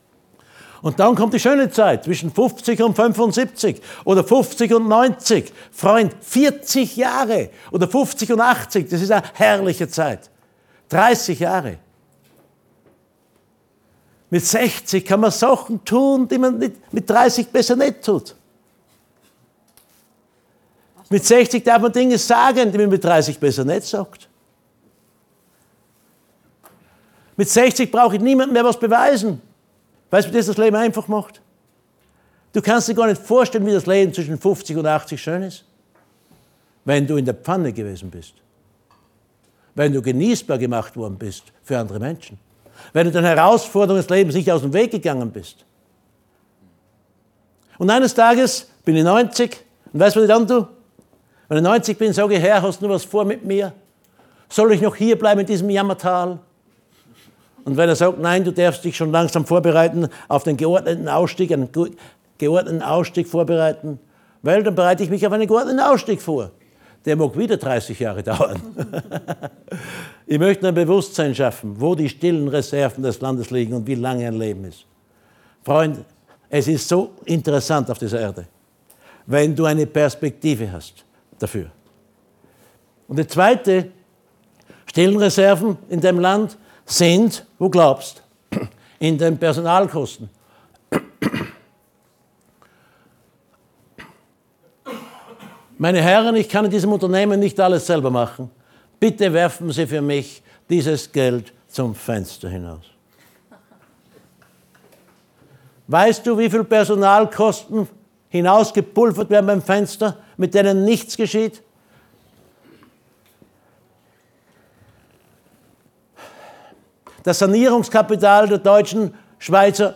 LIVE-Mitschnitt Teil 04